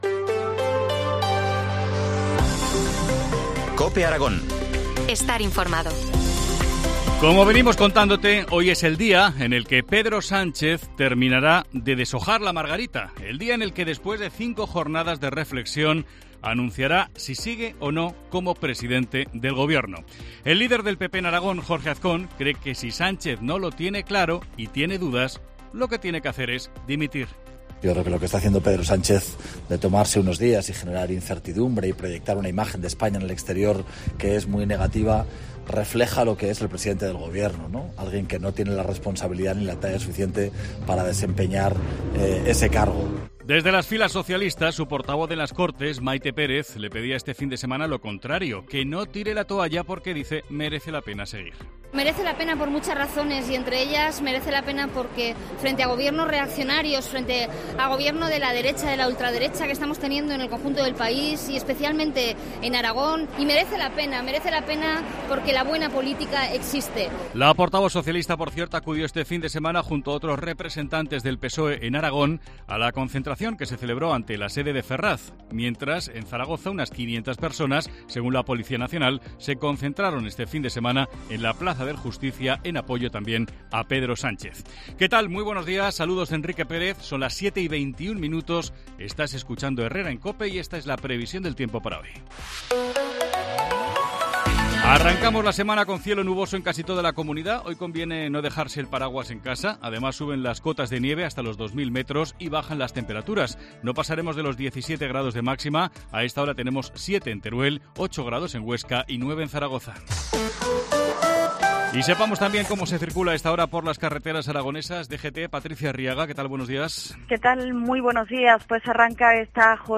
Titulares del día en COPE Aragón